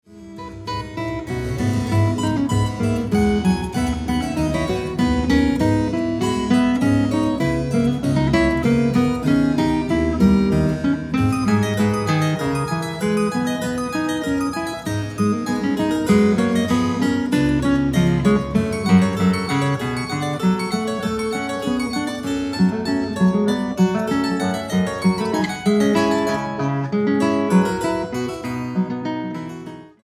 guitarra
clavecín
música original para guitarra y clavecín